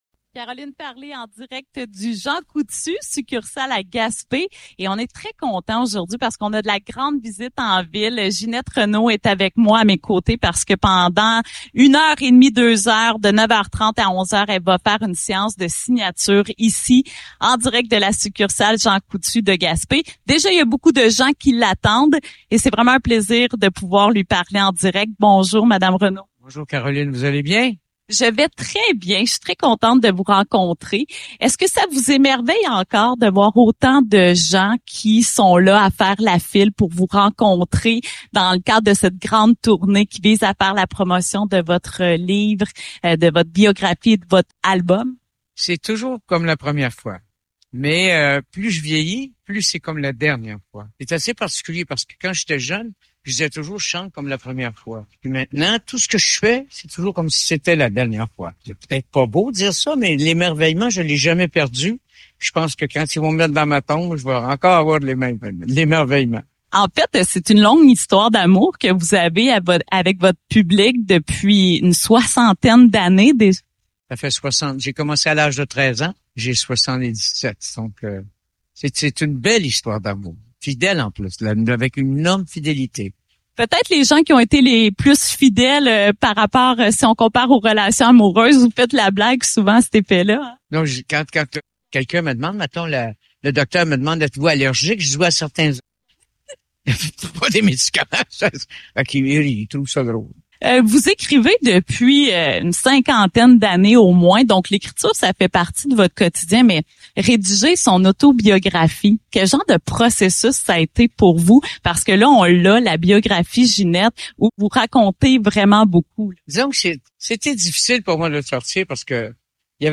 Fidèle à son habitude, Mme Reno a été d’une grande générosité lors de cette entrevue.